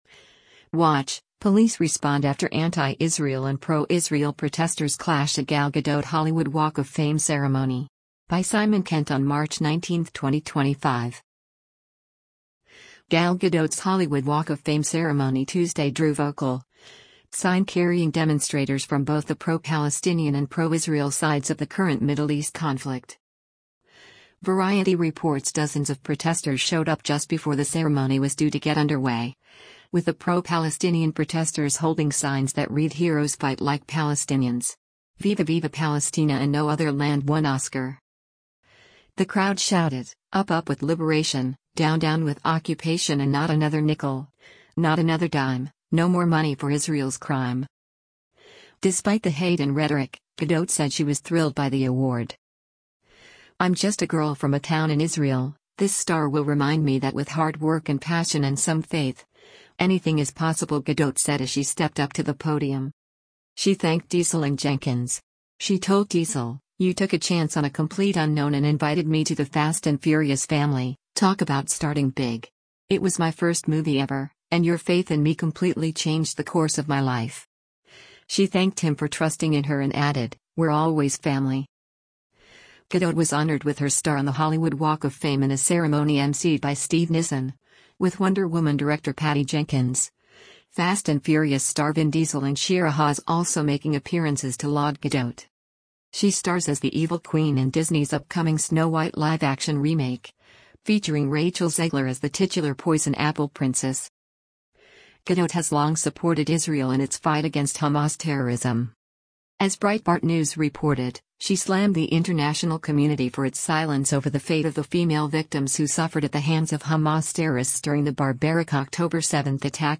Watch: Police Respond After Anti-Israel and Pro-Israel Protesters Clash at Gal Gadot Hollywood Walk of Fame Ceremony
Gal Gadot‘s Hollywood Walk of Fame ceremony Tuesday drew vocal, sign-carrying demonstrators from both the pro-Palestinian and pro-Israel sides of the current Middle East conflict.
The crowd shouted, “Up up with liberation, down down with occupation” and “Not another nickel, not another dime, no more money for Israel’s crime.”